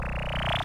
warpout5.mp3